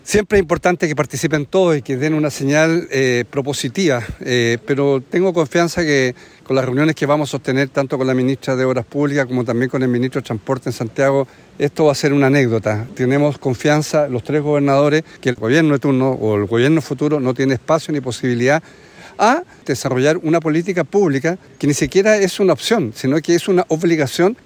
A los pies del Volcán Chaitén, en medio de la característica llovizna del sur, los gobernadores de la zona sur austral del país, firmaron su compromiso para trabajar en pos de la conectividad denominada “Chile por Chile”.